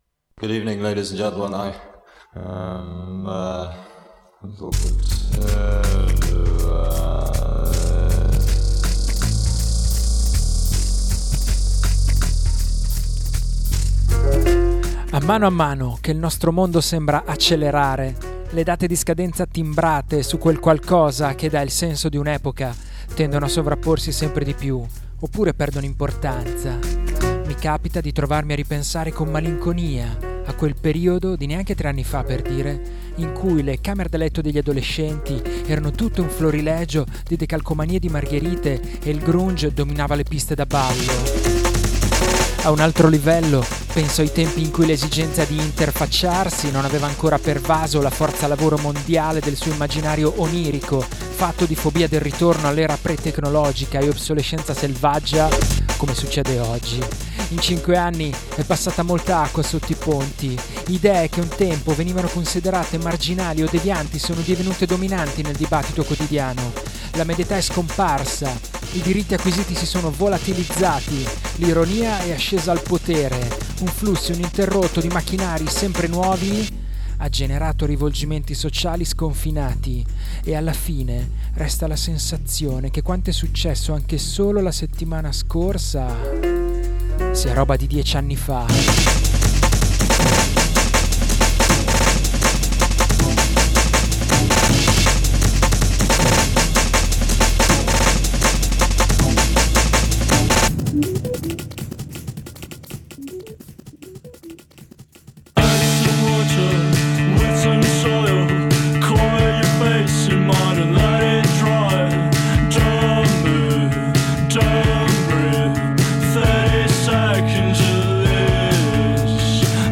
Novità discografiche indiepop, indie rock, shoegaze, post-punk, lo-fi e twee